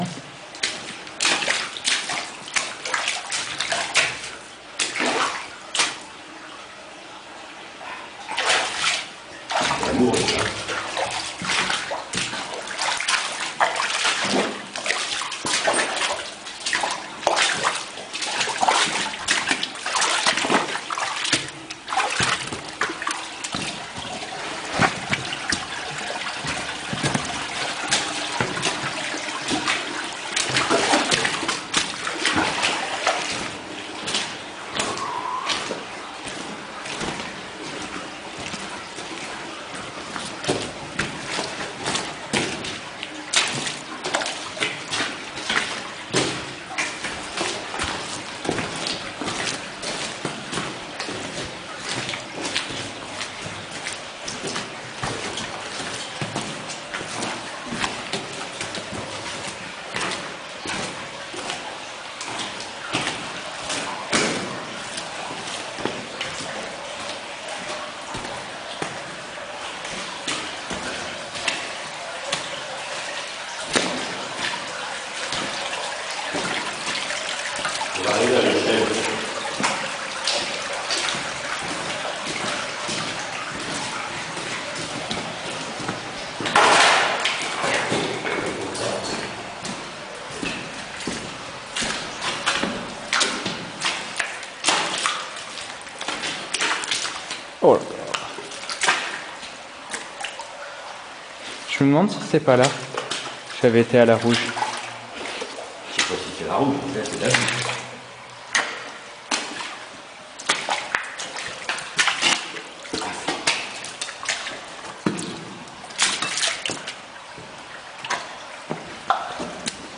04-laboue.mp3